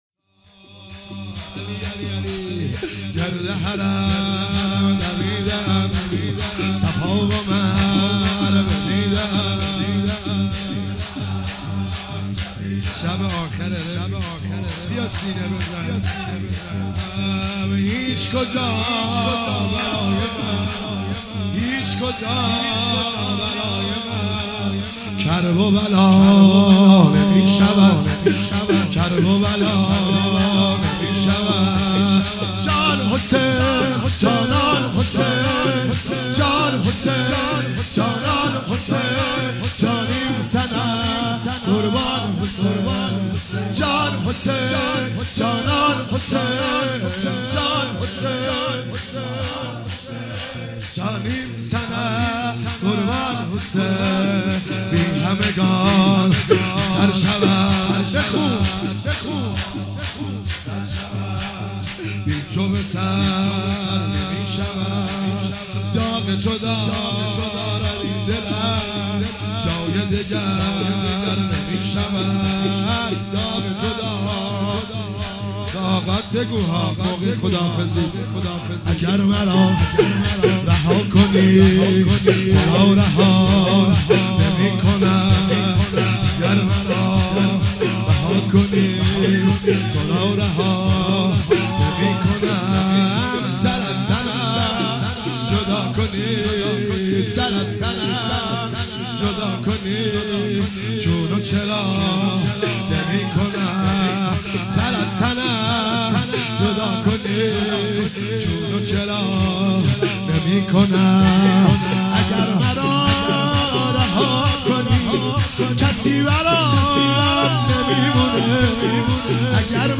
شب آخر صفر97 - شور - گرد حرم دویده ام